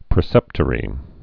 (prĭ-sĕptə-rē, prēsĕp-)